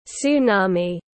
Sóng thần tiếng anh gọi là tsunami, phiên âm tiếng anh đọc là /tsuːˈnɑː.mi/.
Tsunami /tsuːˈnɑː.mi/
Tsunami.mp3